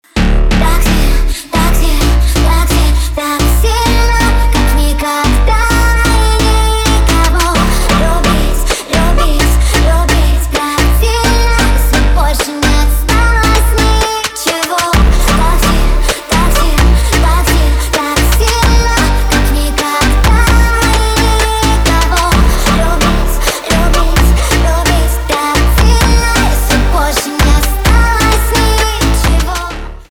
фонк